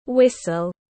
Cái còi tiếng anh gọi là whistle, phiên âm tiếng anh đọc là /ˈwɪs.əl/